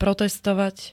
Zvukové nahrávky niektorých slov
e5c5-protestovat.ogg